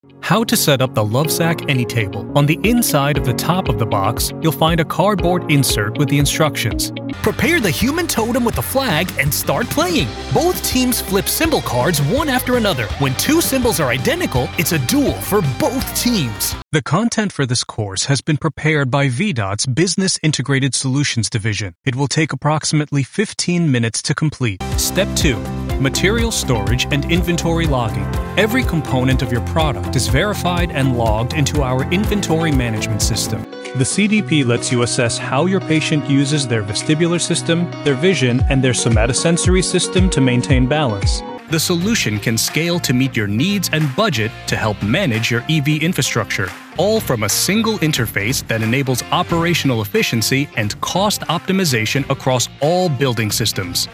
Male
E-Learning/Explainer Pro Reel
Words that describe my voice are conversational, relatable, genuine.